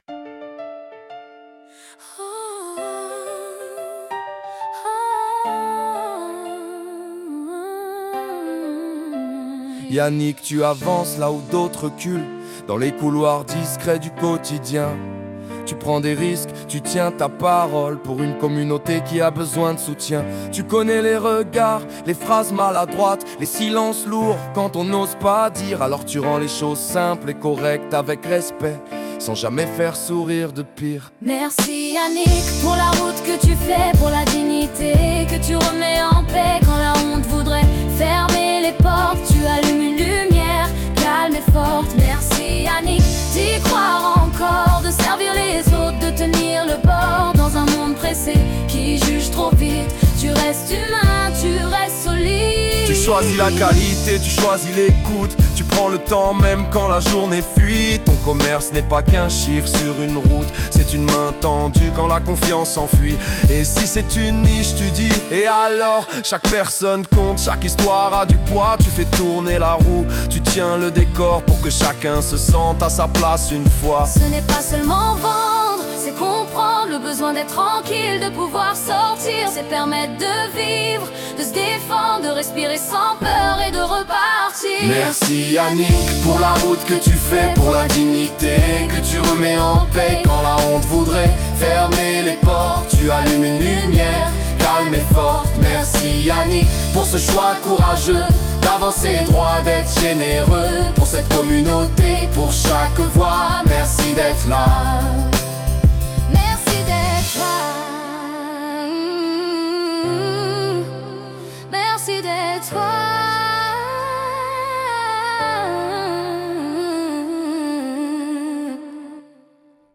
Genre: Pop / Rap
Vocal: KI (Suno Premier)